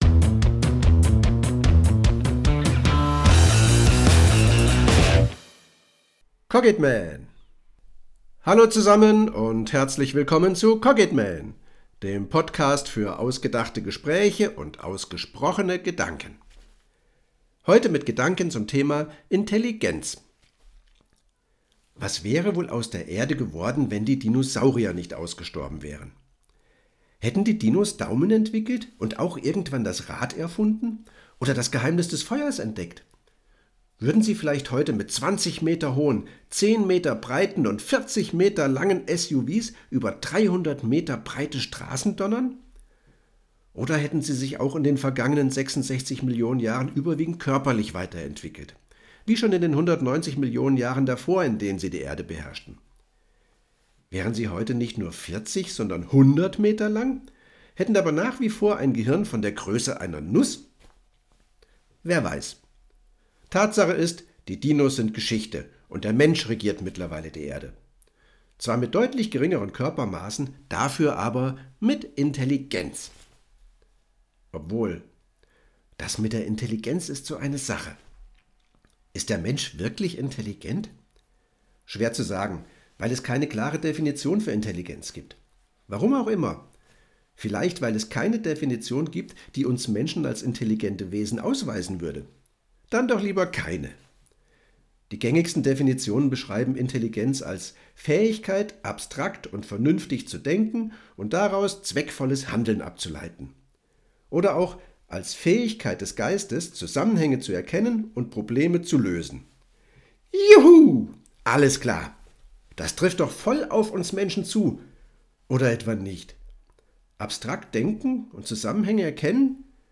Dialog-Intelligenz.mp3